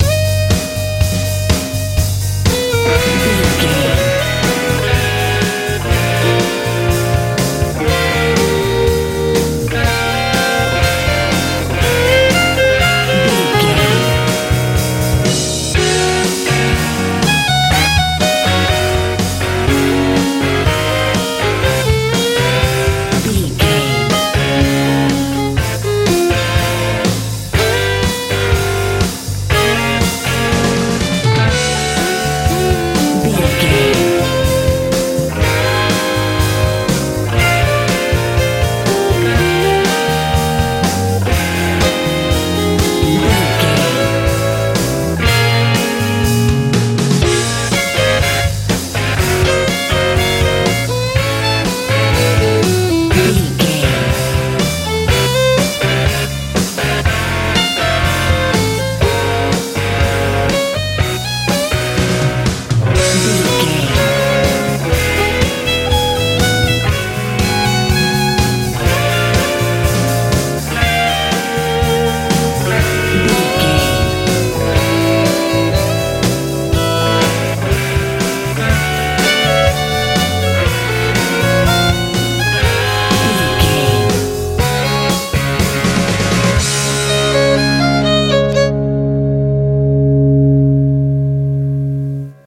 med rock
Ionian/Major
E♭
driving
cool
electric guitar
violin
bass guitar
drums
southern